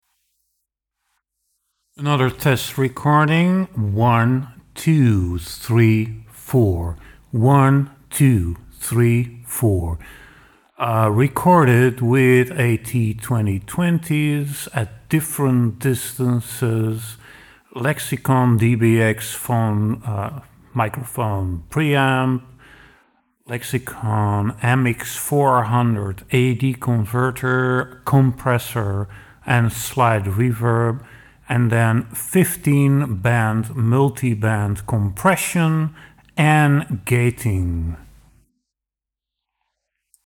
Works great to make the audio fuller sounding (even though of course a lot fo CD might better be dynamically expanded instead of compressed, which they already are..).
I mades some test microphone recordings (with Audio Technica AT 2020s), and synthesizer recording with somehow the above in the audio path (or in the added effect path):